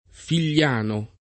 Figliano [ fil’l’ # no ]